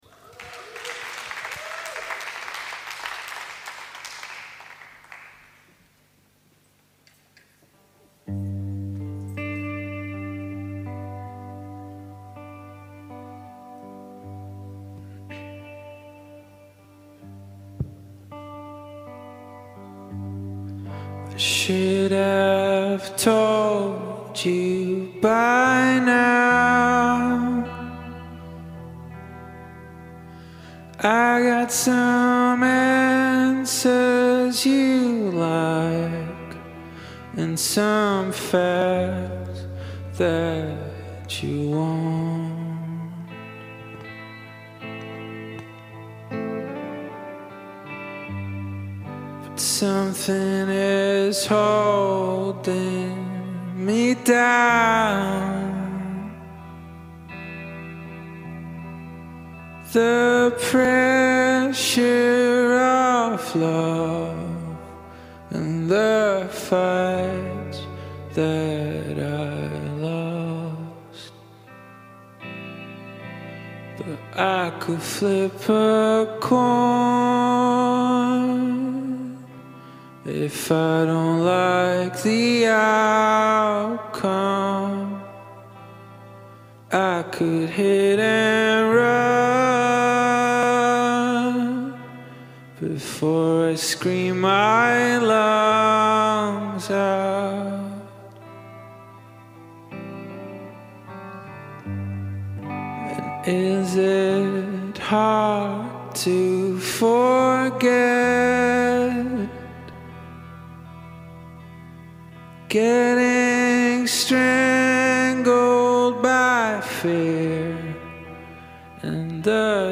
dreamy alt-indie